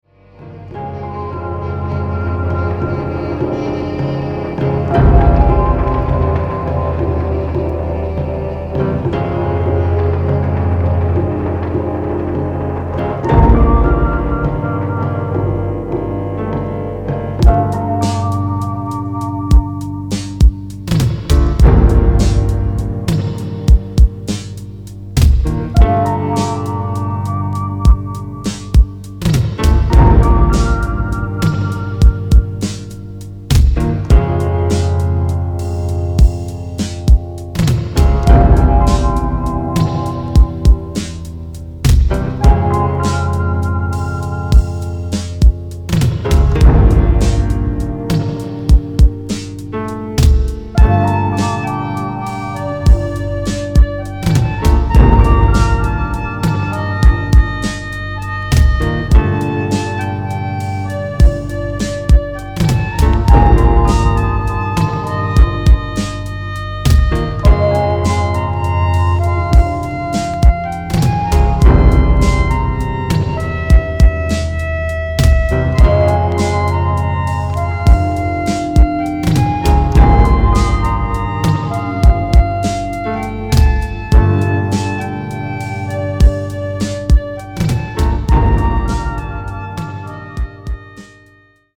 宅録　ミニマル